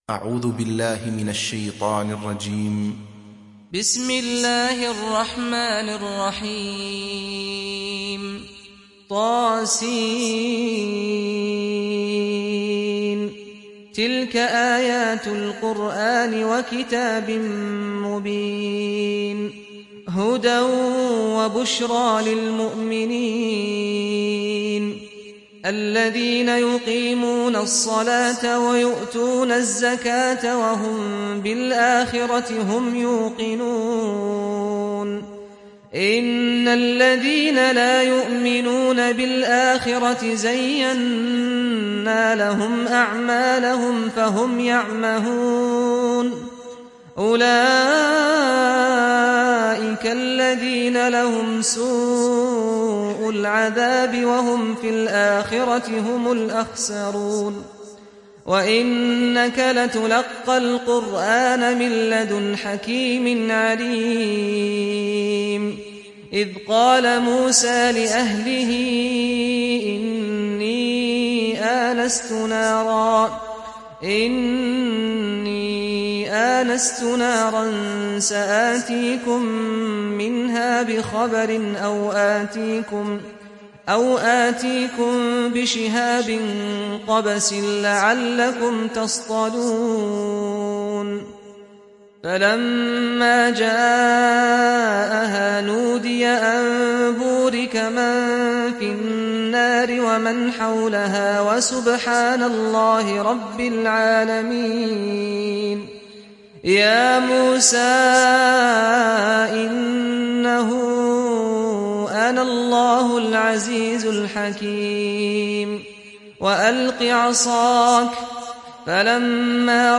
تحميل سورة النمل mp3 بصوت سعد الغامدي برواية حفص عن عاصم, تحميل استماع القرآن الكريم على الجوال mp3 كاملا بروابط مباشرة وسريعة